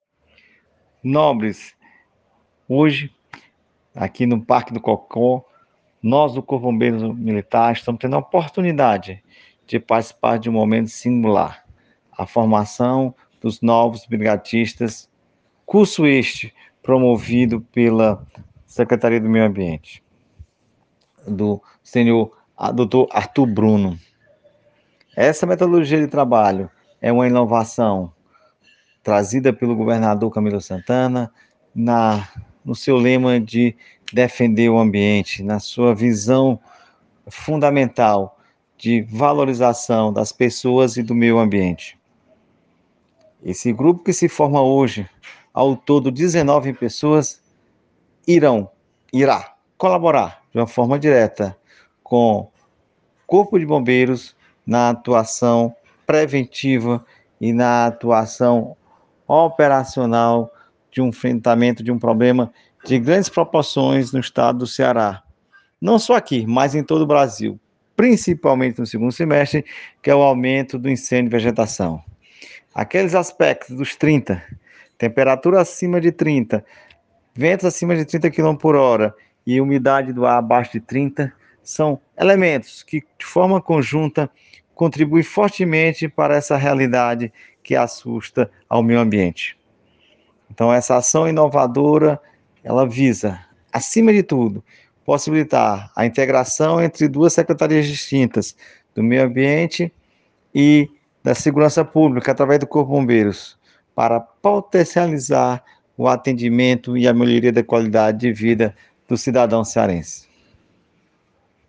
Áudio do Coronel Comandante-Geral do Corpo de Bombeiros Militar do Estado do Ceará, Ronaldo Roque de Araújo.